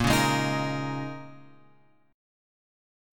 A# Minor 11th